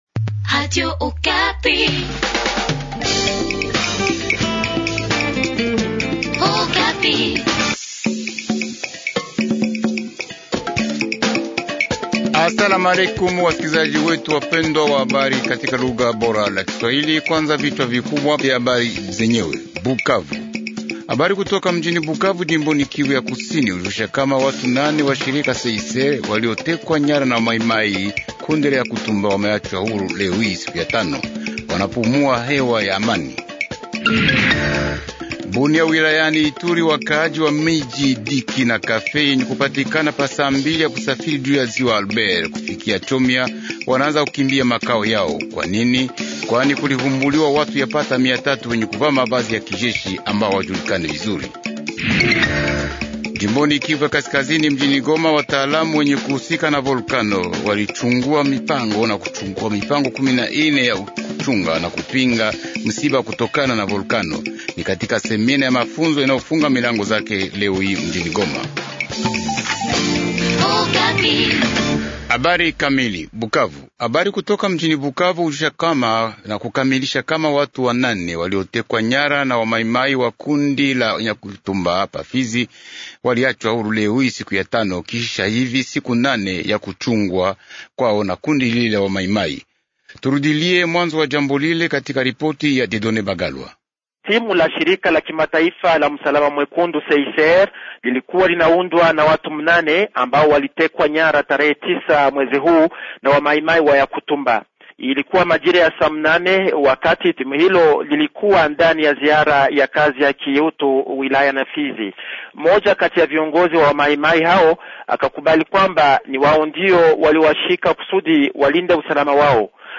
Journal swahili du soir